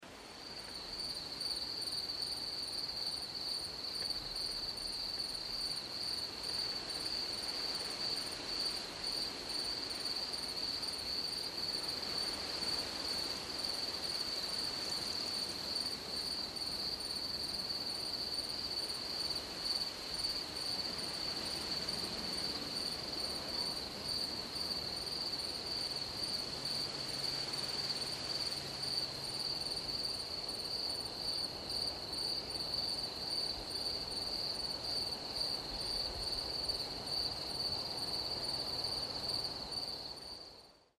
Здесь вы найдете стрекотание в разных тональностях, от одиночных особей до хора насекомых.
Ветер и стрекотание саранчи